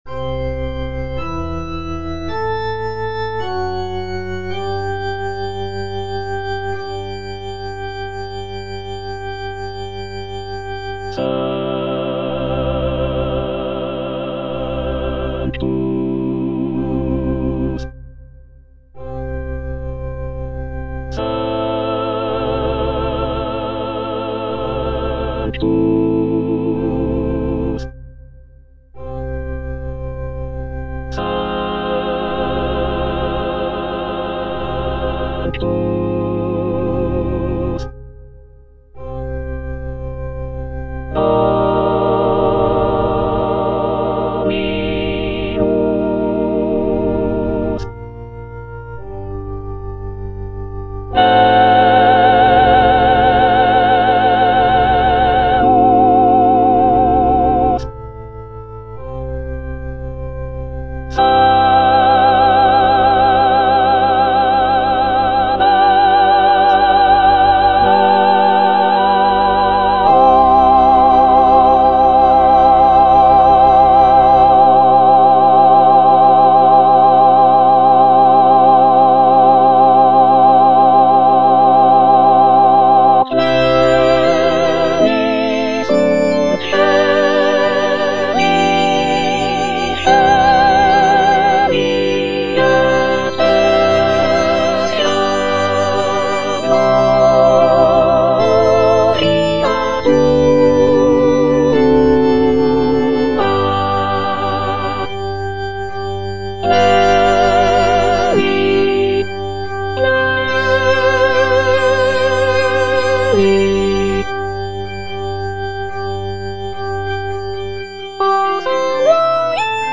The work features a grand and powerful sound, with rich harmonies and expressive melodies.
C.M. VON WEBER - MISSA SANCTA NO.1 Sanctus (soprano I) (Emphasised voice and other voices) Ads stop: auto-stop